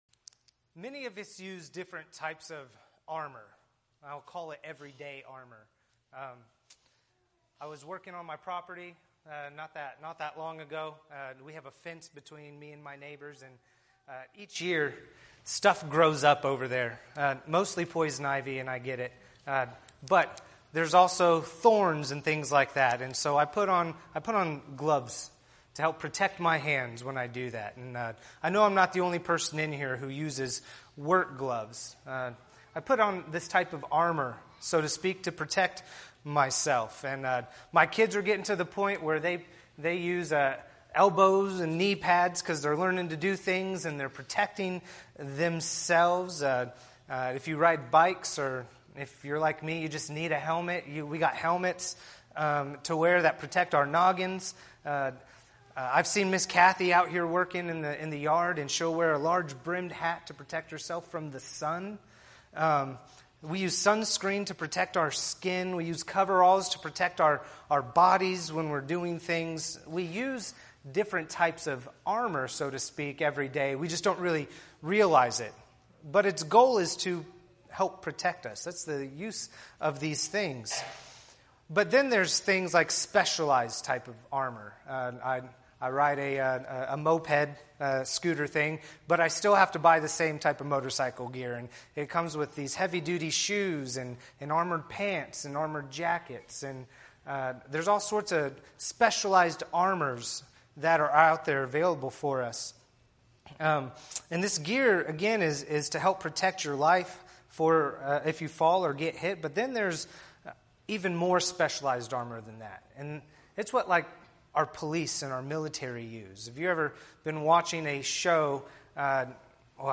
Podcasts Videos Series Sermons The Whole Armor of God